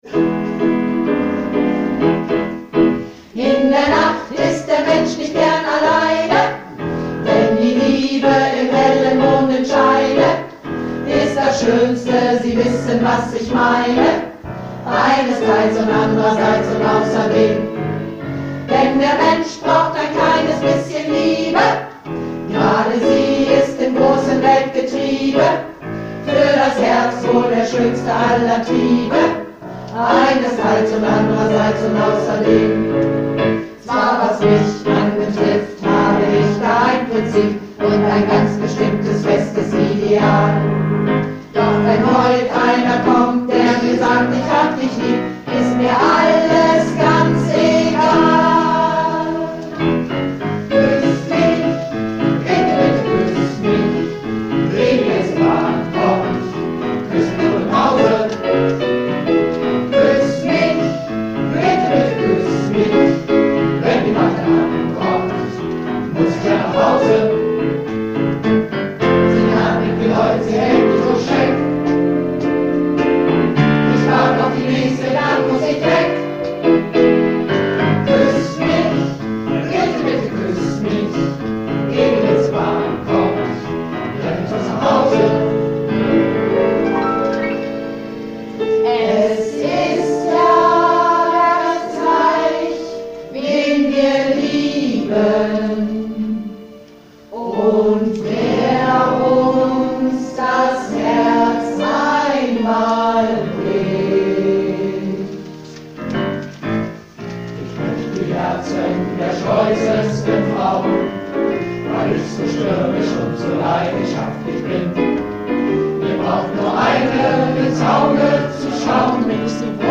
Malle Diven - Chorwochenende (19.01.19)
Projektchor
Frauen-M�nner-Medley